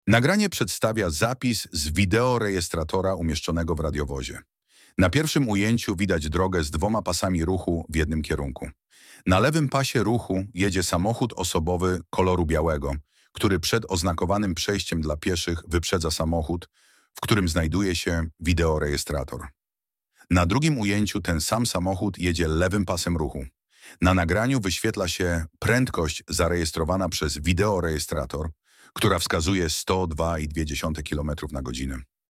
Audiodekskrypcja do nagrania - plik mp3